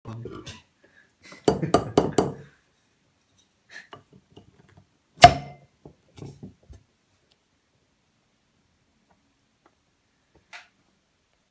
sonor, solide
Plöppgeräusch